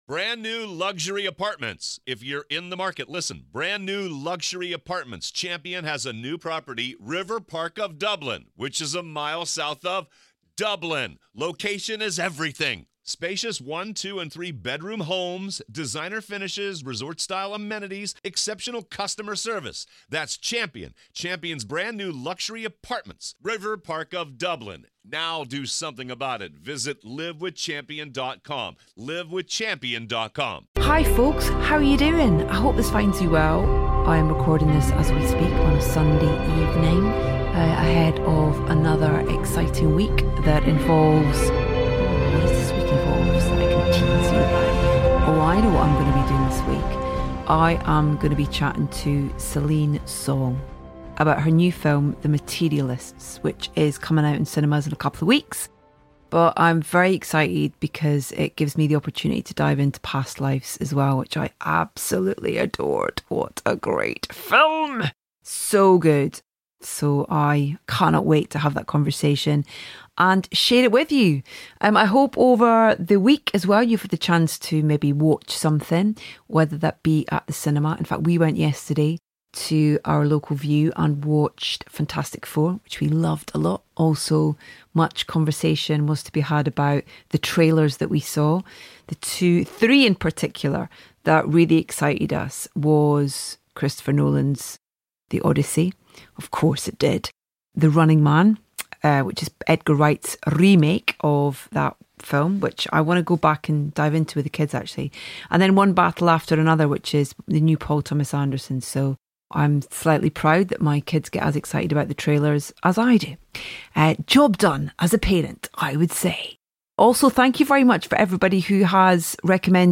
We have a double header of you to start your week, as both composers who worked on James Gunn's Superman join me to discuss their collaboration on the film.